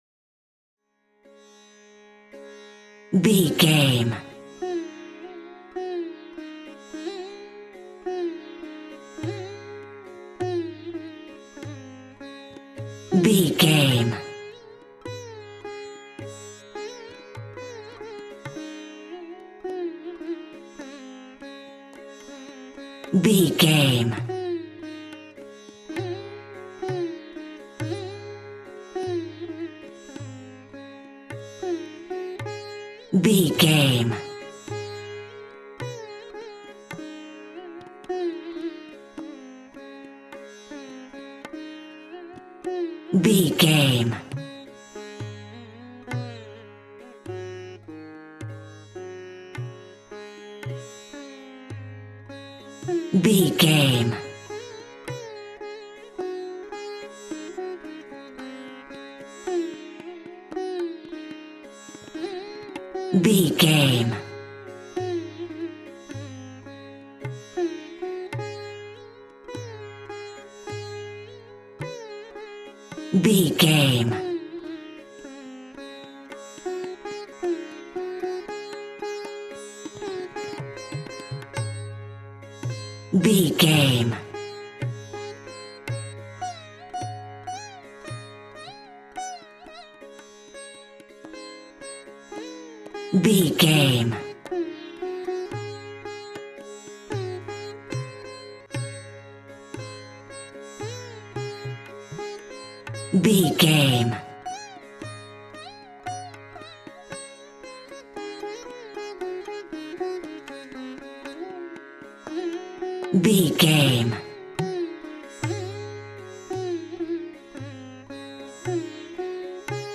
Ionian/Major
D♭
Slow
World Music